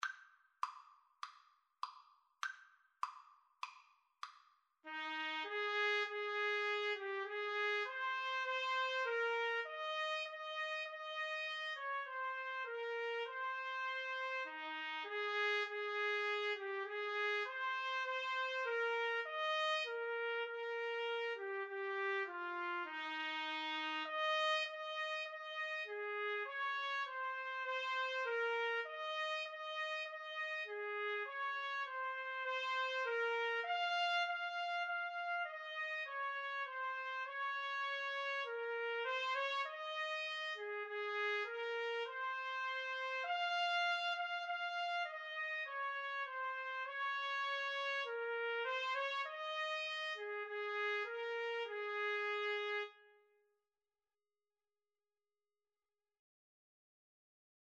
4/4 (View more 4/4 Music)
Trumpet Duet  (View more Easy Trumpet Duet Music)